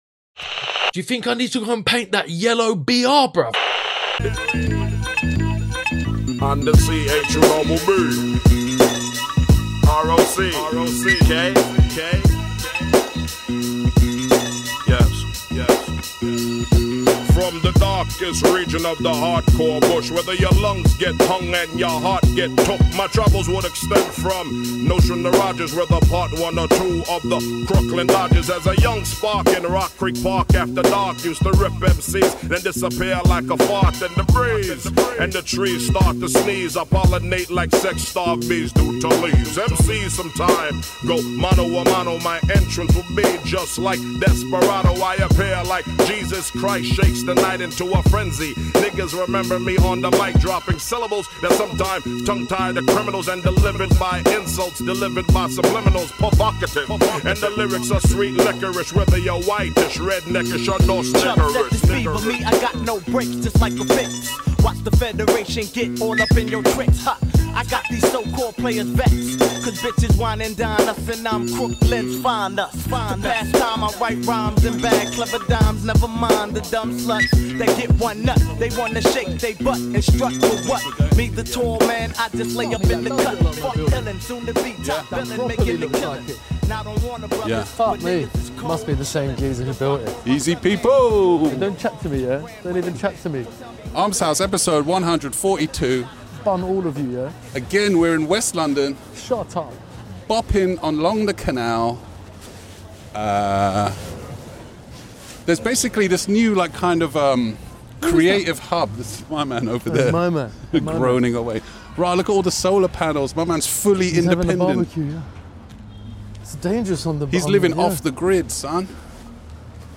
Walking down a canal in West London chatting about the Graff there then we check out Legendary DJ Tony Touch spin some tunes... Graff Critique too.... Enjoy!